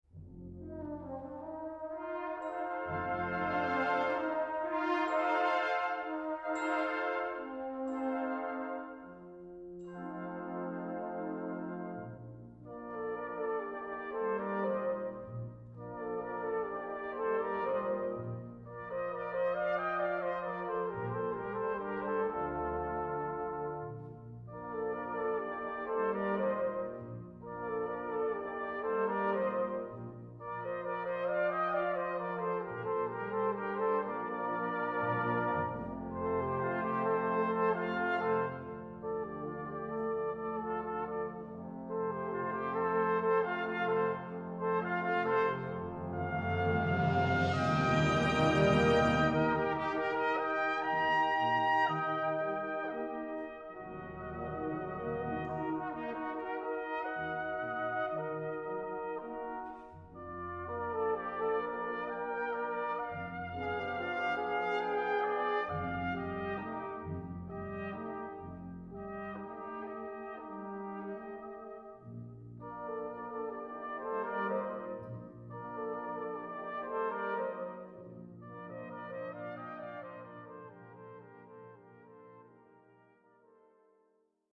Cornet, Bugle et Brass Band